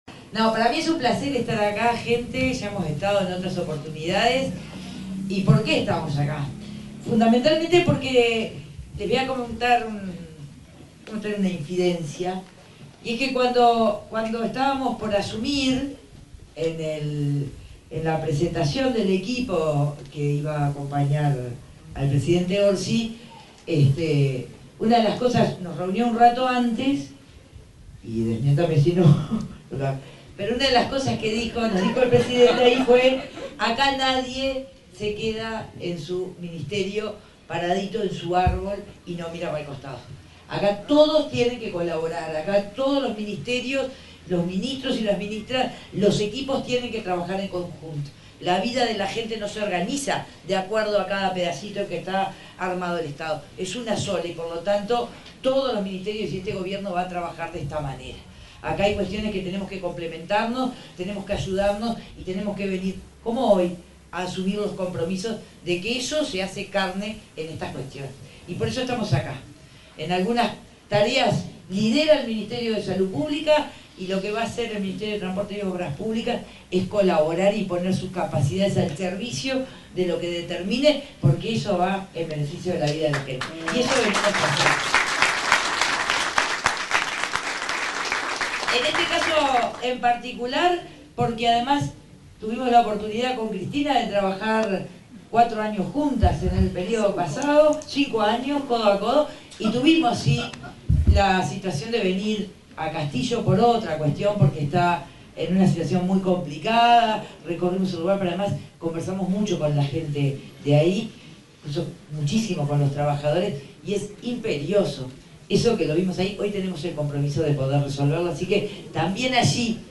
Palabras de la ministra de Transporte, Lucía Etcheverry
Palabras de la ministra de Transporte, Lucía Etcheverry 02/05/2025 Compartir Facebook X Copiar enlace WhatsApp LinkedIn La ministra de Transporte, Lucía Etcheverry, participó, este viernes 2 en el centro auxiliar del Chuy, departamento de Rocha, en el lanzamiento de la Comisión Binacional Asesora de Frontera.